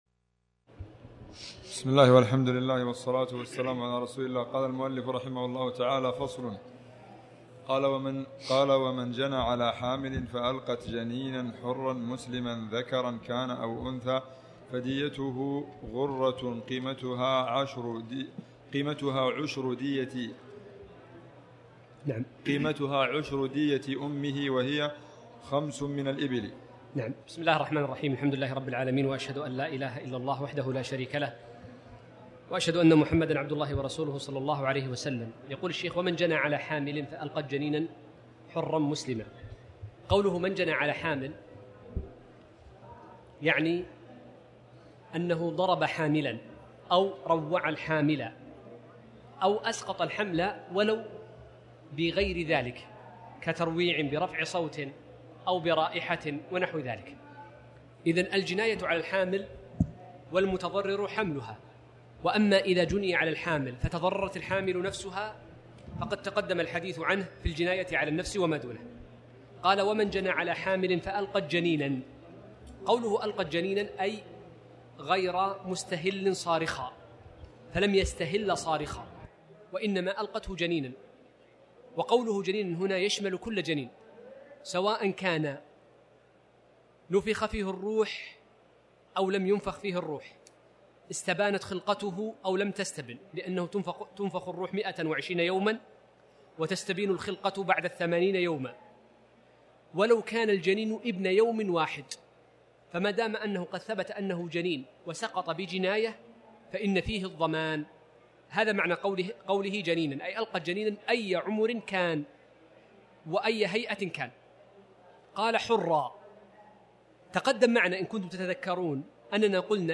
تاريخ النشر ٣ رجب ١٤٣٩ هـ المكان: المسجد الحرام الشيخ